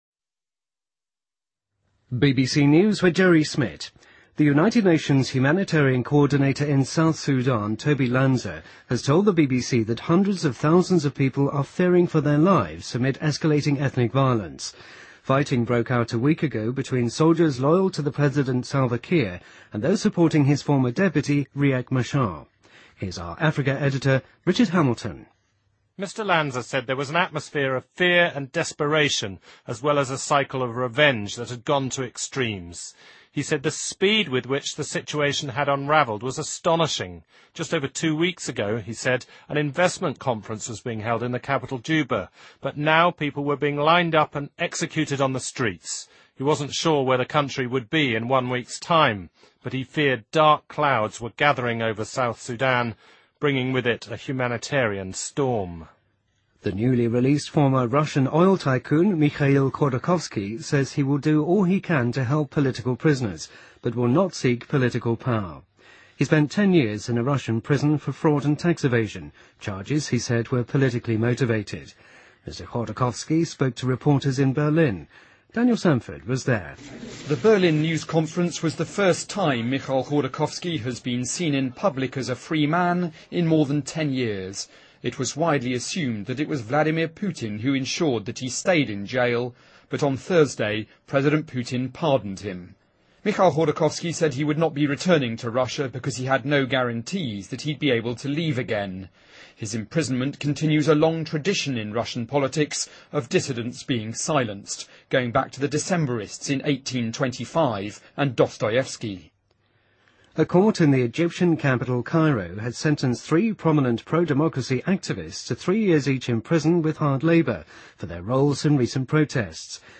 BBC news,2013-12-23